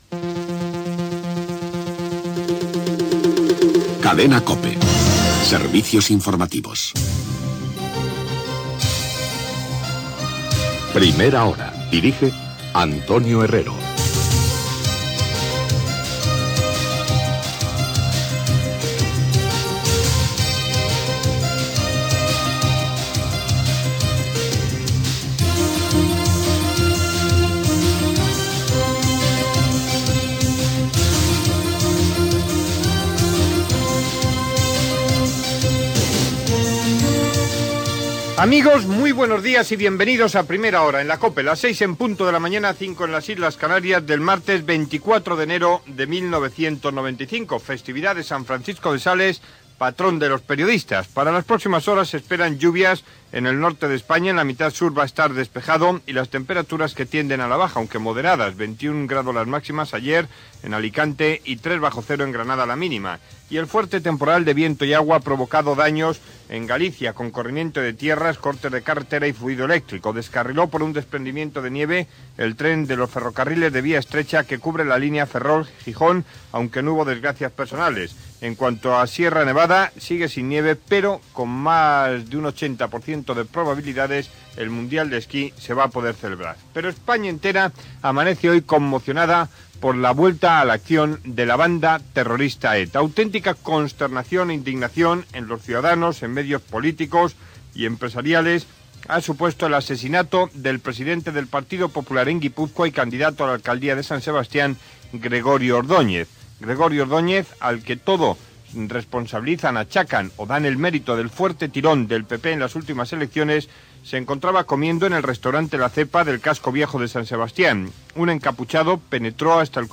Careta, hora, data, santoral, el temps, informació de l'assassinat del polític del Partido Popular Gregorio Ordóñez per la banda terrorista ETA, entrevista a Carlos Garaikoetxea, president d'Eusko Alkartasuna Gènere radiofònic Info-entreteniment Presentador/a Herrero, Antonio